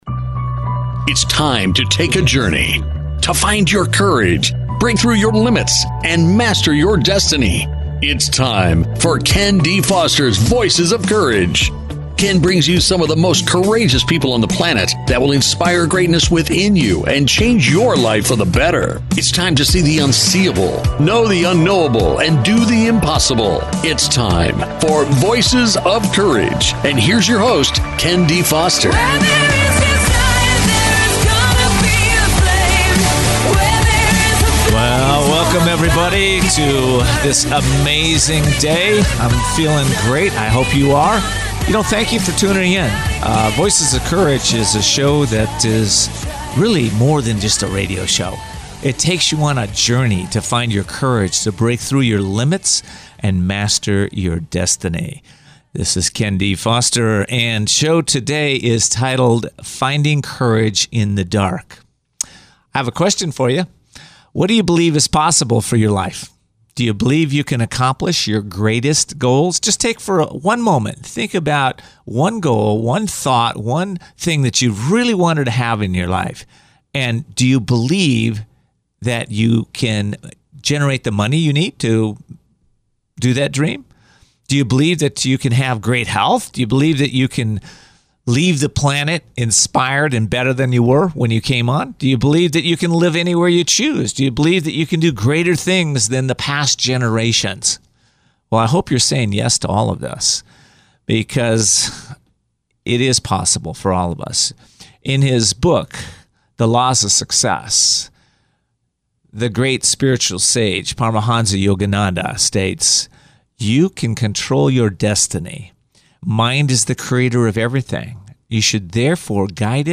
Subscribe Talk Show